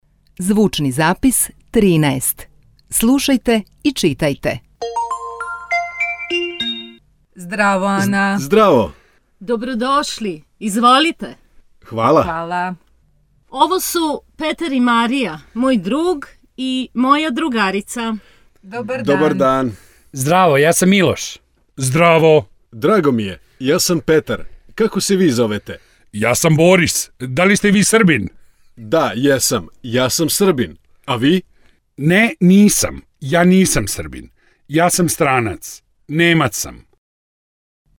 Zbirka audio-zapisa prilagođenih brzinom i vokabularom početnim nivoima predstavlja izuzetno važan i veoma bogat segment udžbenika.